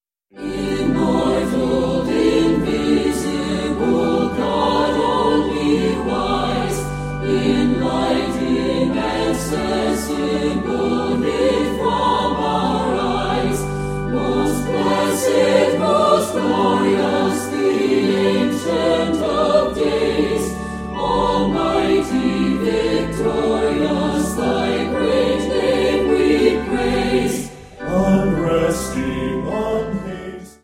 mit leichter instrumentaler begleitung
• Sachgebiet: Praise & Worship